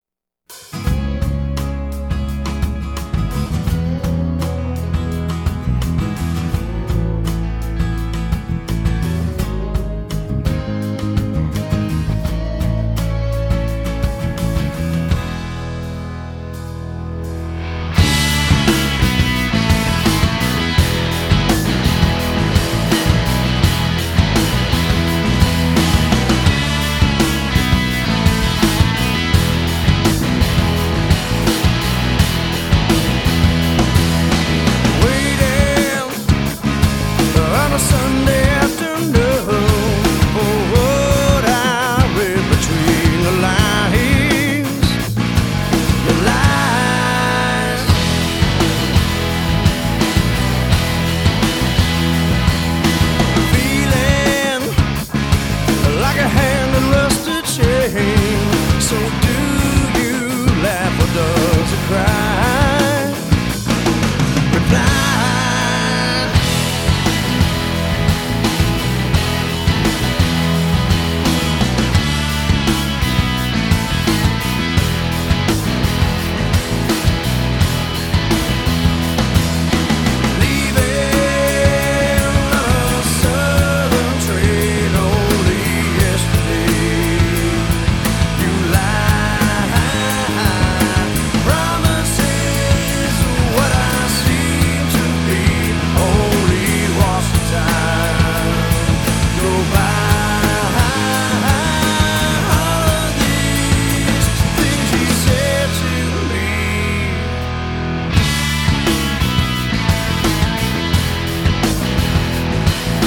融入爵士、藍調、放克等不同風格